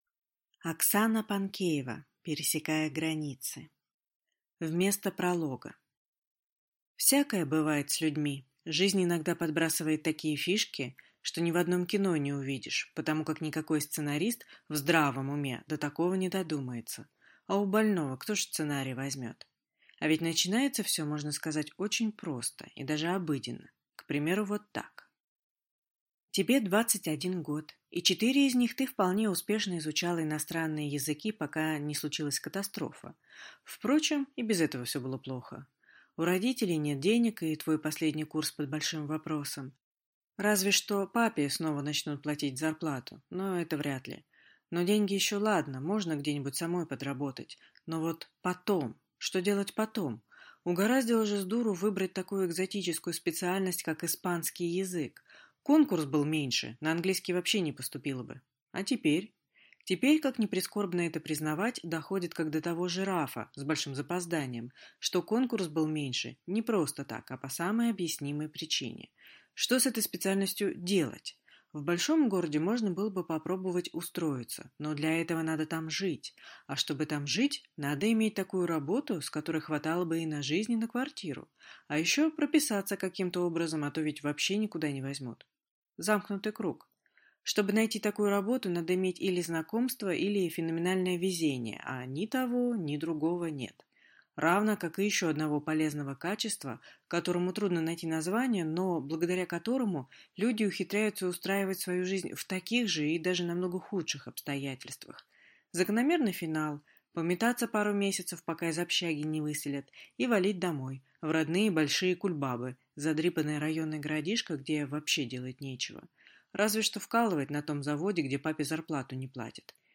Аудиокнига Пересекая границы | Библиотека аудиокниг